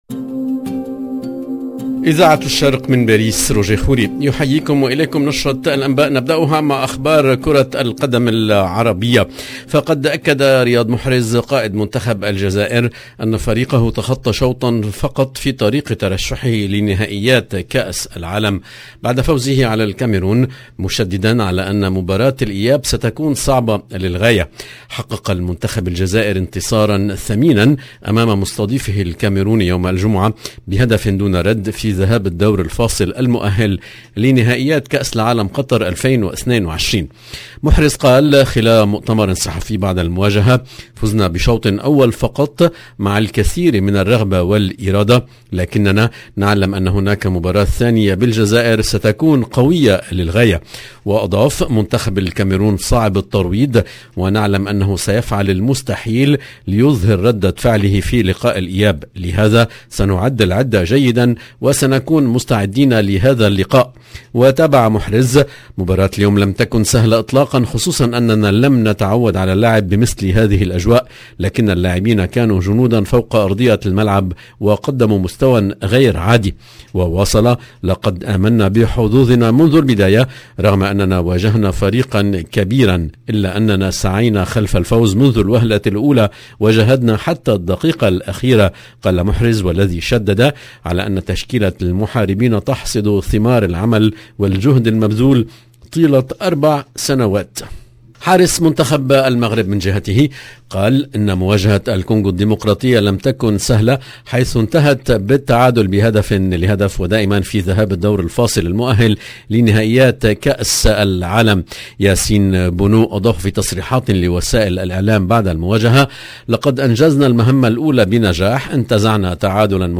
LE JOURNAL SOIR EN LANGUE ARABE DU 26/03/22